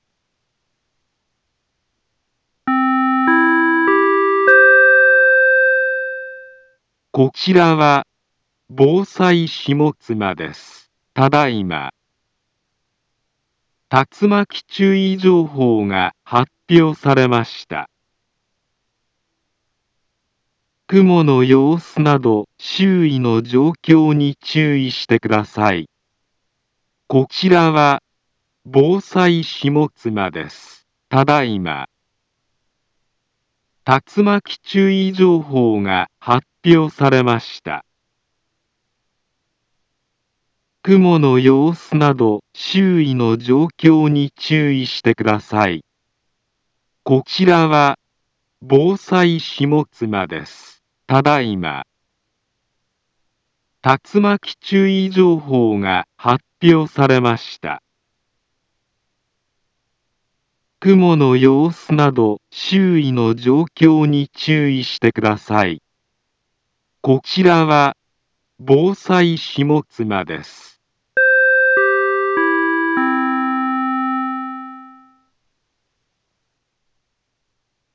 Back Home Ｊアラート情報 音声放送 再生 災害情報 カテゴリ：J-ALERT 登録日時：2023-06-28 20:29:39 インフォメーション：茨城県北部、南部は、竜巻などの激しい突風が発生しやすい気象状況になっています。